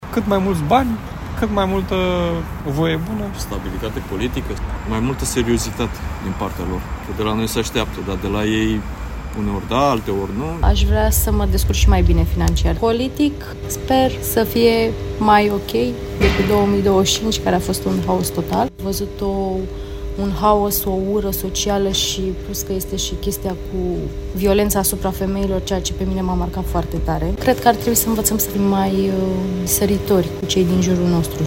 „Mai multă stabilitate politică, mai multă seriozitate din partea lor”, spune un bărbat
„Câți mai mulți bani, cât mai multă voie bună”, spune un bărbat.